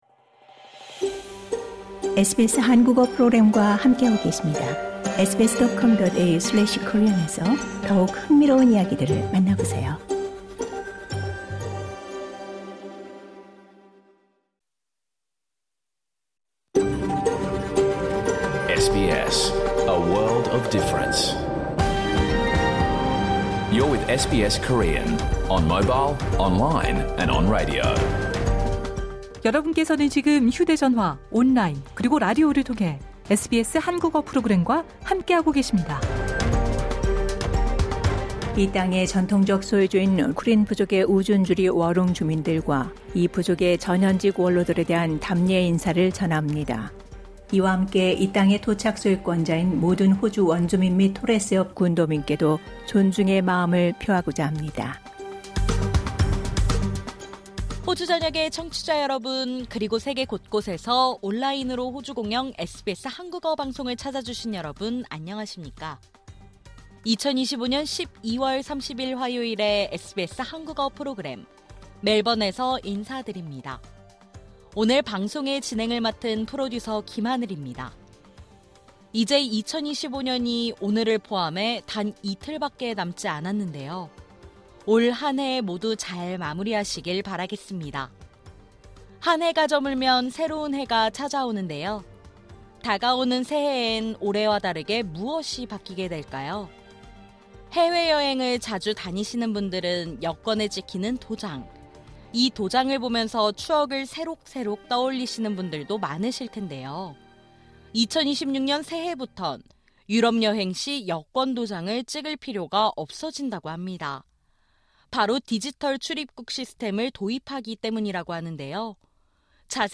2025년 12월 30일 화요일에 방송된 SBS 한국어 프로그램 전체를 들으실 수 있습니다.